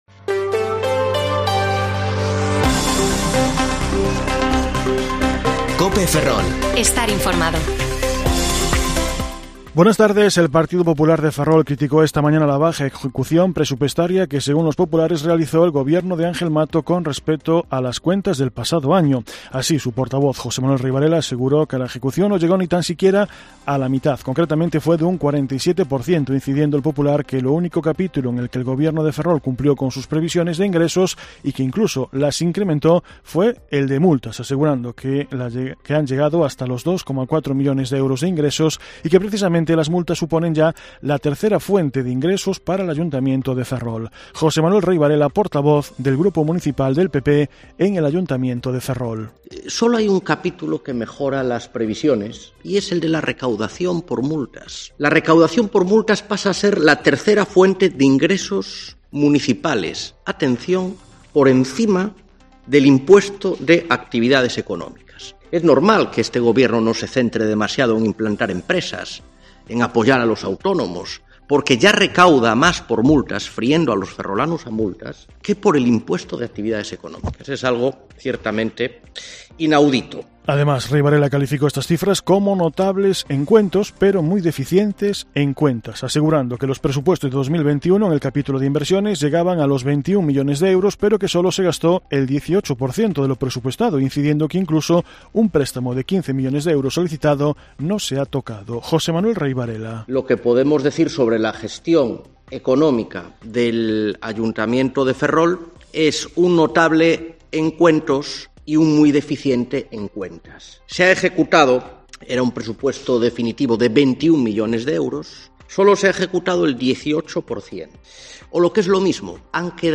Informativo Mediodía COPE Ferrol 14/2/2022 (De 14,20 a 14,30 horas)